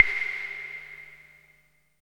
50 CLAVE  -R.wav